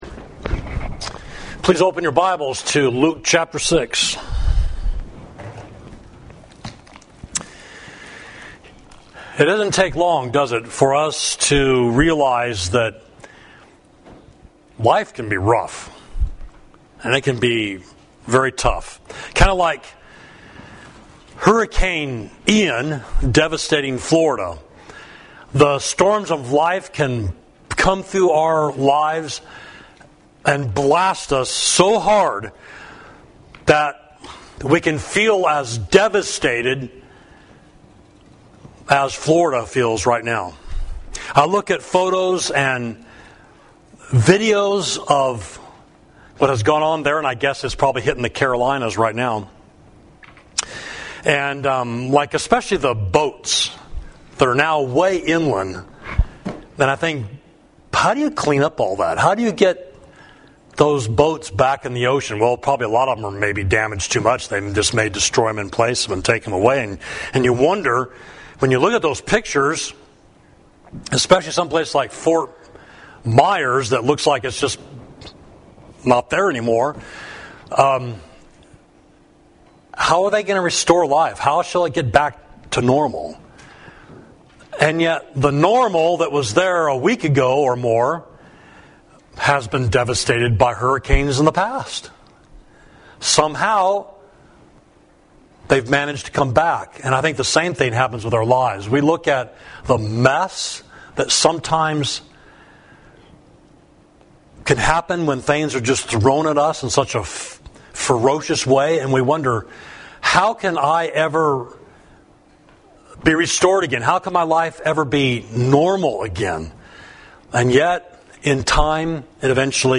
Sermon: How to Build an Unshakeable Life, Luke 6.37–49